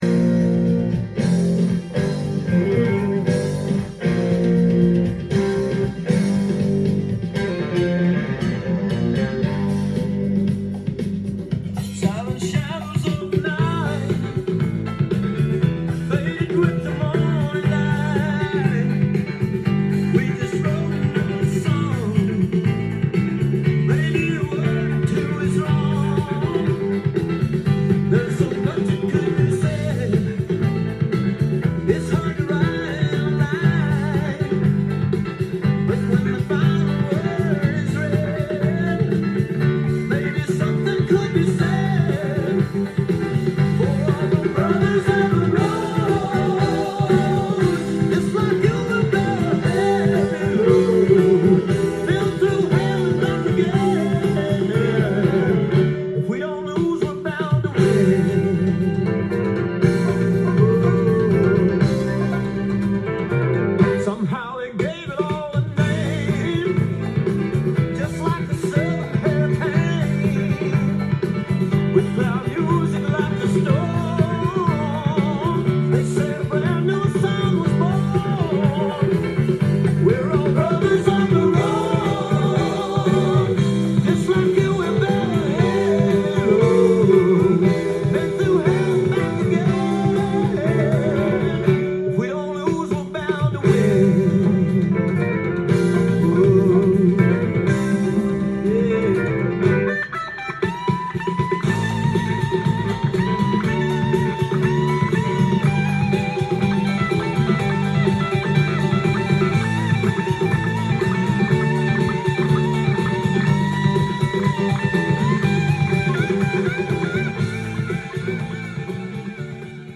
ジャンル：ROCK & POPS
店頭で録音した音源の為、多少の外部音や音質の悪さはございますが、サンプルとしてご視聴ください。
Fiddle